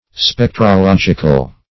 Search Result for " spectrological" : The Collaborative International Dictionary of English v.0.48: Spectrological \Spec`tro*log"ic*al\, a. Of or pertaining to spectrology; as, spectrological studies or experiments.
spectrological.mp3